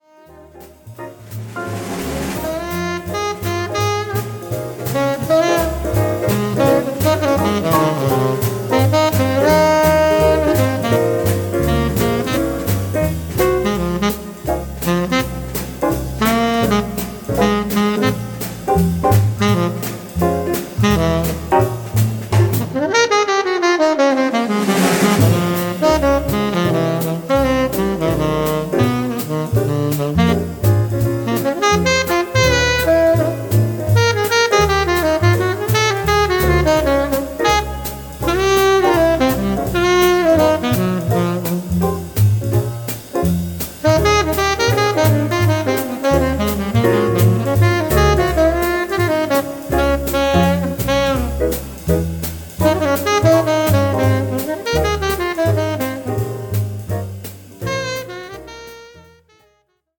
軽快さとゆったりしたムードが同居するブロウがなんとも心地よい1枚です。